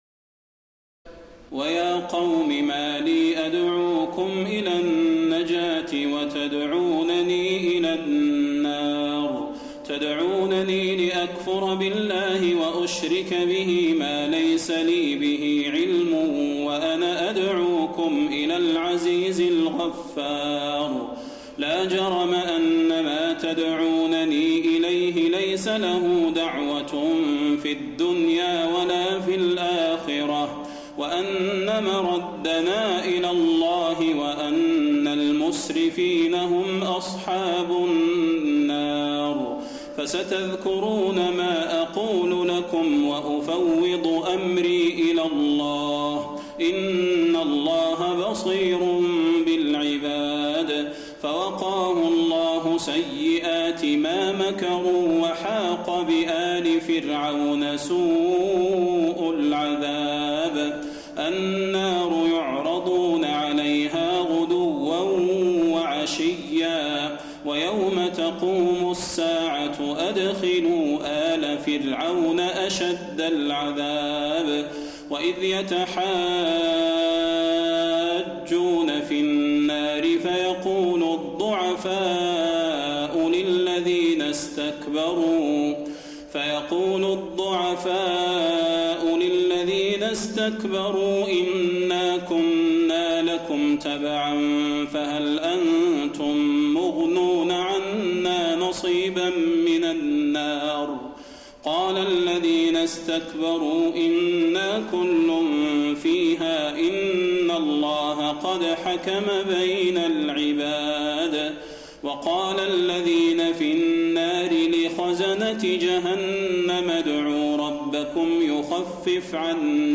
تراويح ليلة 23 رمضان 1432هـ من سور غافر (41-85) وفصلت (1-44) Taraweeh 23 st night Ramadan 1432H from Surah Ghaafir and Fussilat > تراويح الحرم النبوي عام 1432 🕌 > التراويح - تلاوات الحرمين